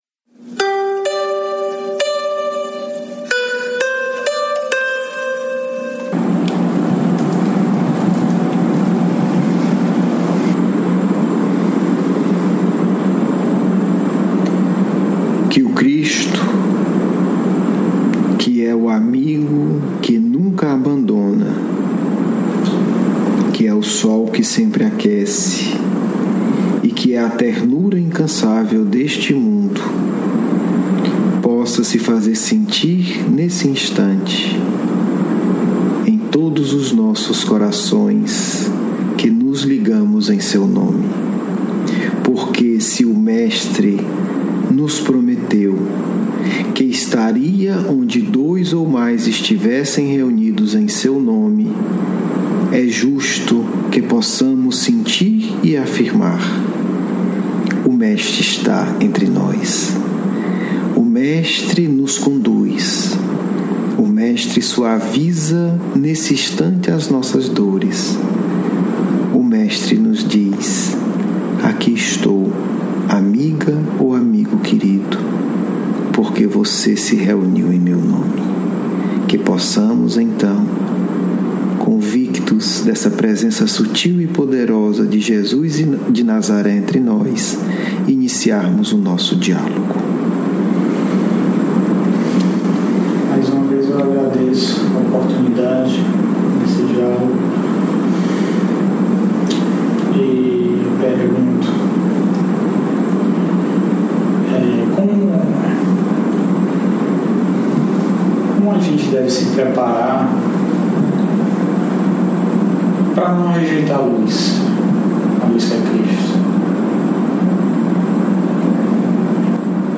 10 – Rejeição da Luz e Cristianismo, hoje Diálogo mediúnico